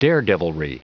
Prononciation du mot daredevilry en anglais (fichier audio)
Prononciation du mot : daredevilry